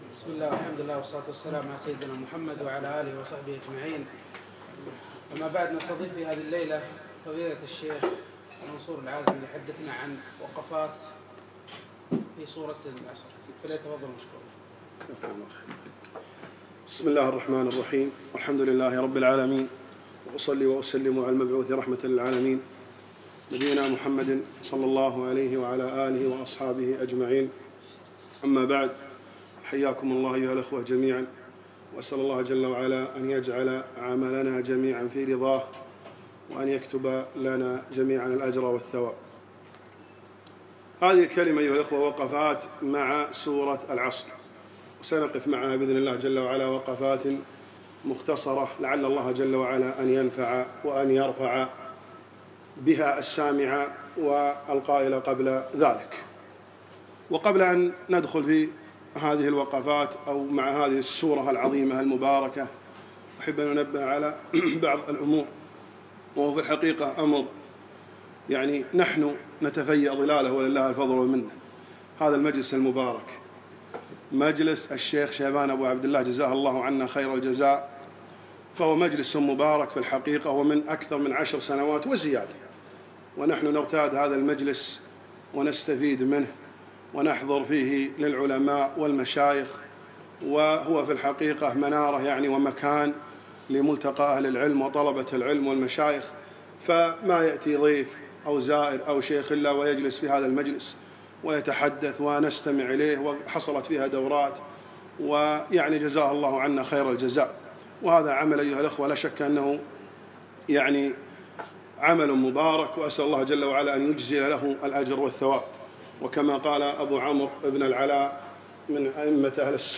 تم القاءهذه المحاضرة